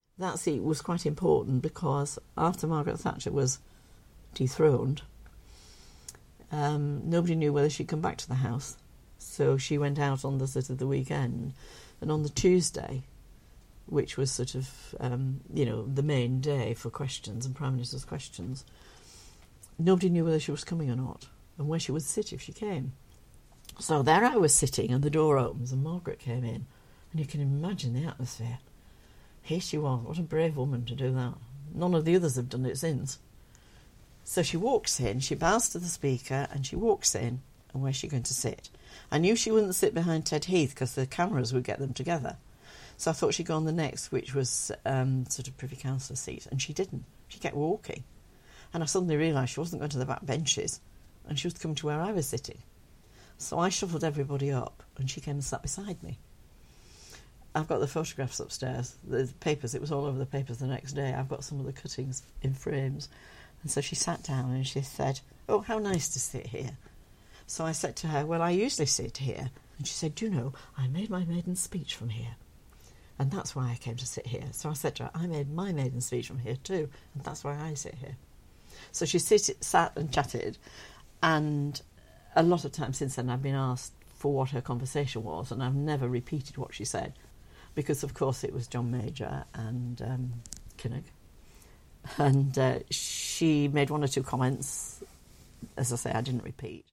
This is reflected in many of our oral history project interviews with former MPs.
Dame Marion Roe, MP for Broxbourne, described the period as ‘very emotional’, whilst the MP for Batley and Spen Elizabeth Peacock was full of admiration for Thatcher as she returned to the Commons the week after her resignation, as she explains in this clip: